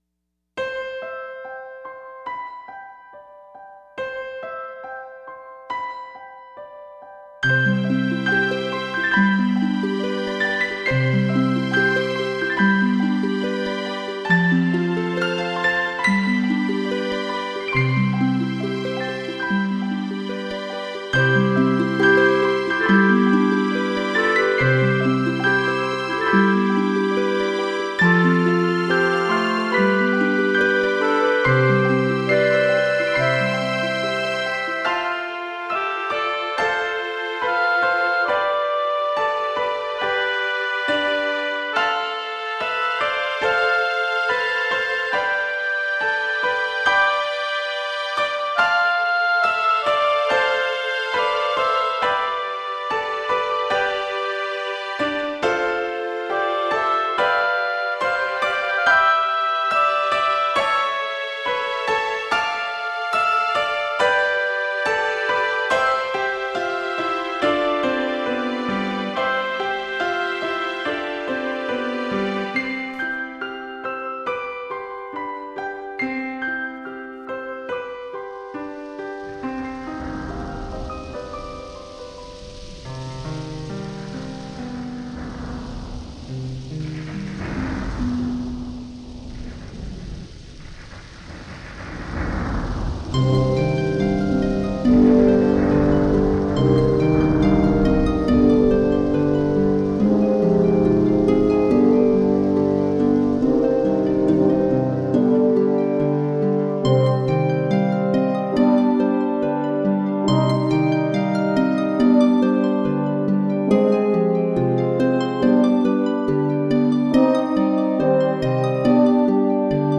Thematic, instrumental/orchestral piece(Part 1 of 3)